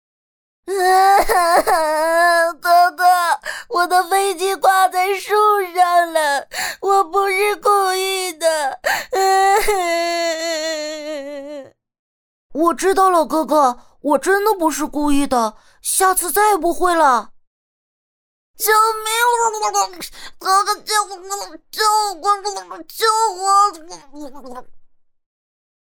仿男童-女242-小男孩.mp3